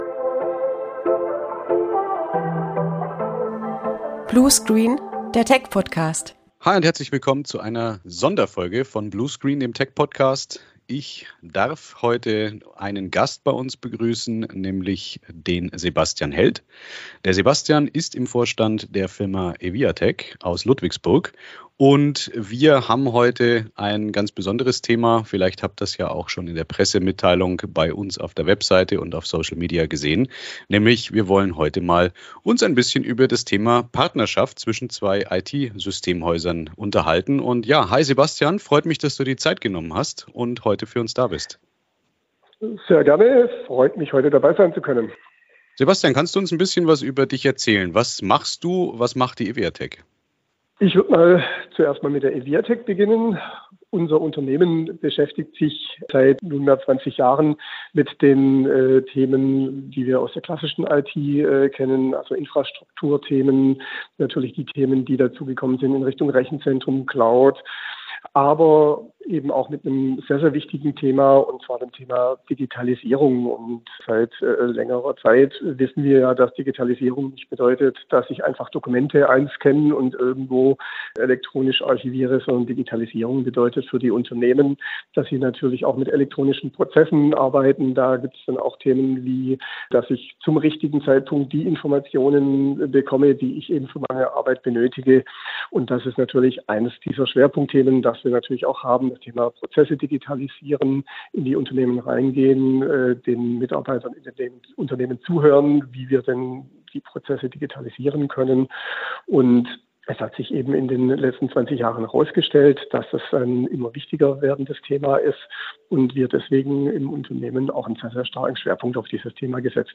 Der erste Sonderfolge von Bluescreen - Der Tech-Podcast! Im Interview: